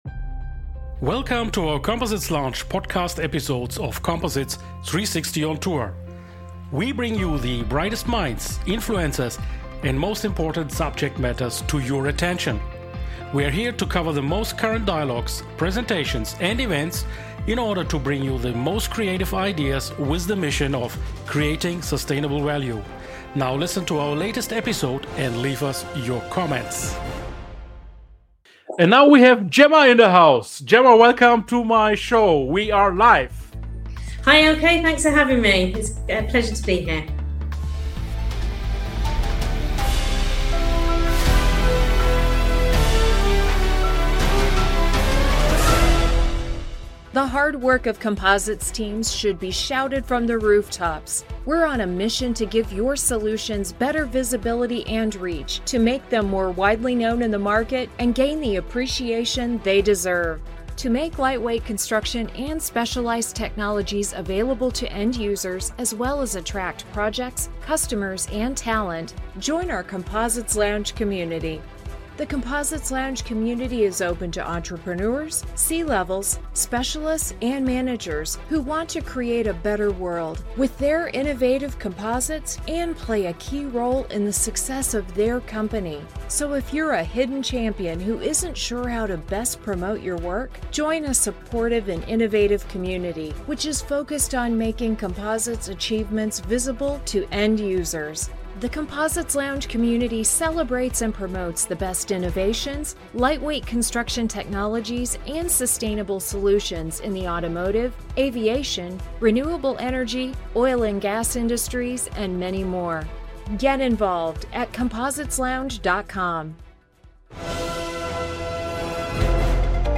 #191 International Composites Summit 2025: Interiew